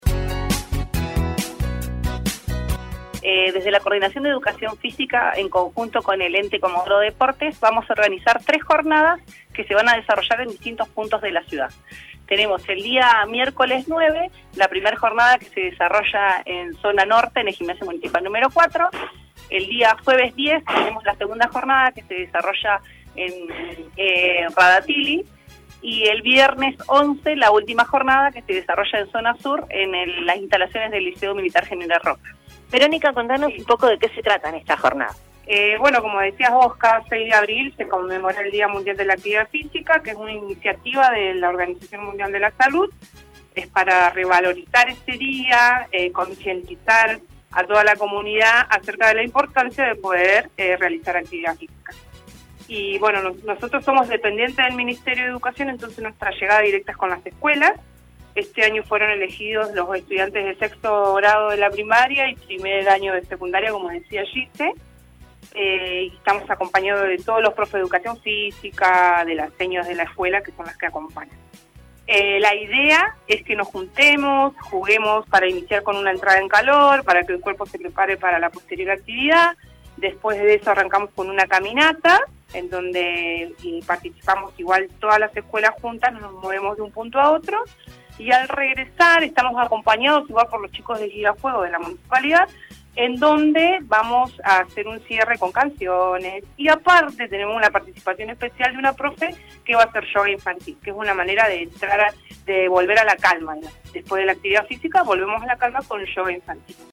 en los micrófonos de Radiovision